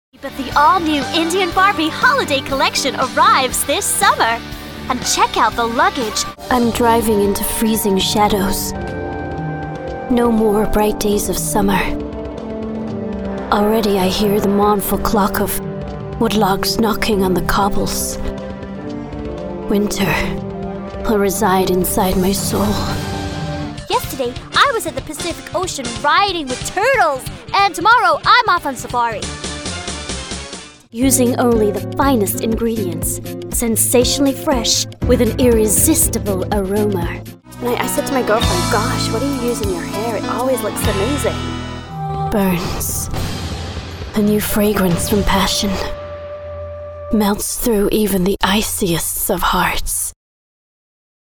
US Commercial Reel
Fresh, crisp and youthful
Based in London with a great home studio.